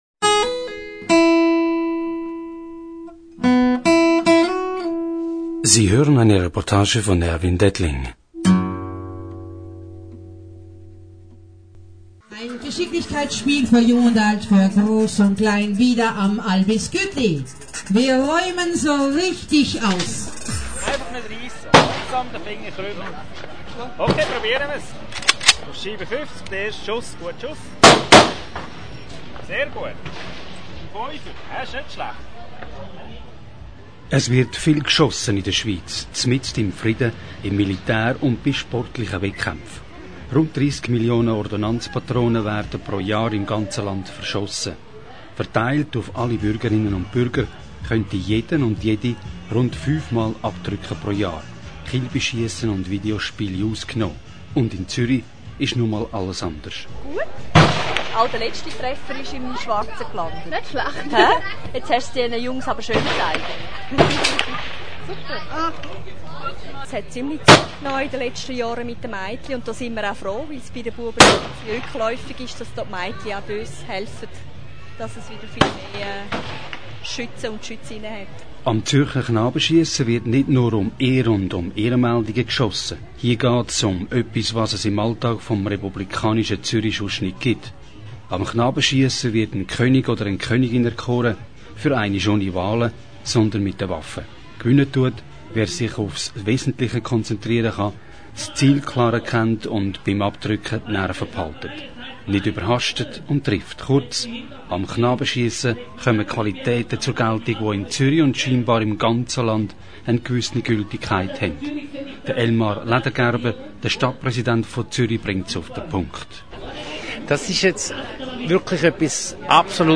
Was hat es auf sich, dass wir Eidgenossen so innig gern abdrücken? Hören Sie die Reportage zum Thema Weitere Reportagen